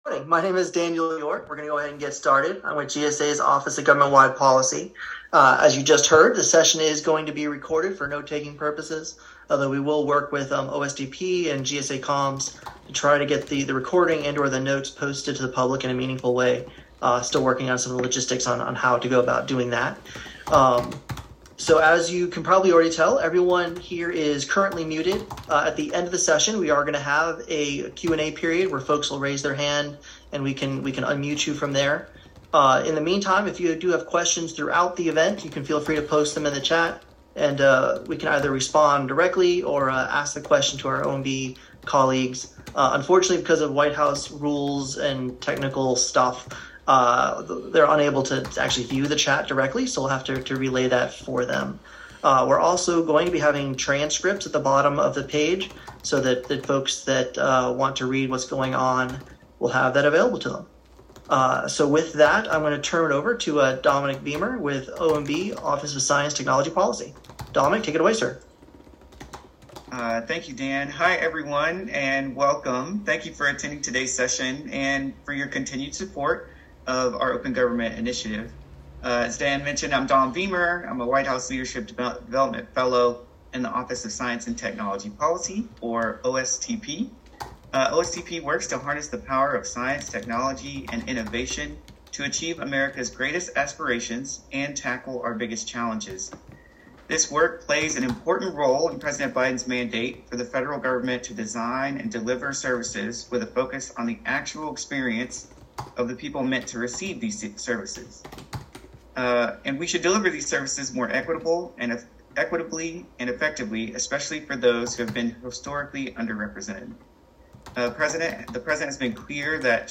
This post contains an audio recording (above) of a public Zoom call held on July 20, 2023 to update Americans on select commitments in the 5th U.S. National Action Plan for Open Government, more than six months after the White House published the plan online at the end of December 2022.
Officials at the White House Office of Management and Budget (OMB) and the Office of Information and Regulatory Affairs (OIRA) presented on their work on commitments to make the voices of the public heard.